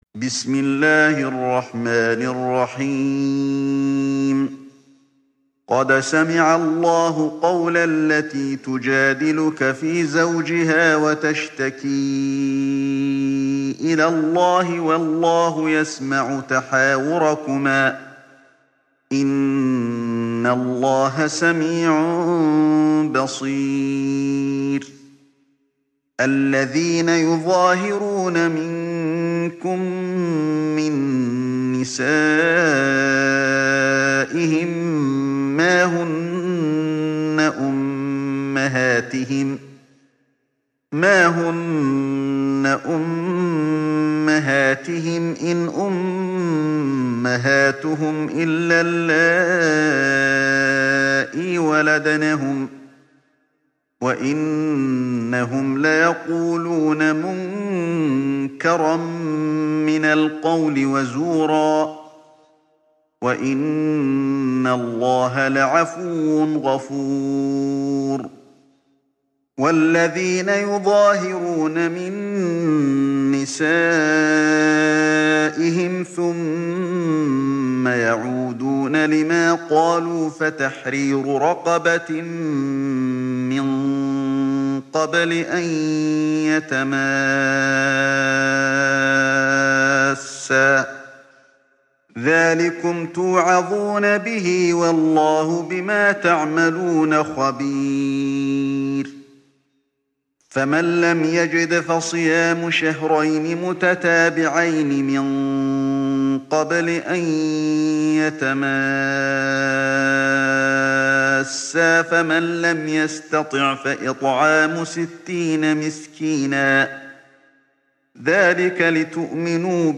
تحميل سورة المجادلة mp3 بصوت علي الحذيفي برواية حفص عن عاصم, تحميل استماع القرآن الكريم على الجوال mp3 كاملا بروابط مباشرة وسريعة